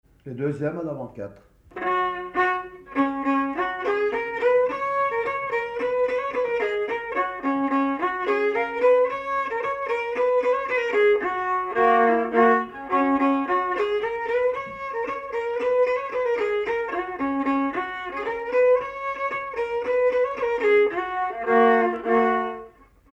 Mazurka partie 3
danse : mazurka
circonstance : bal, dancerie
Pièce musicale inédite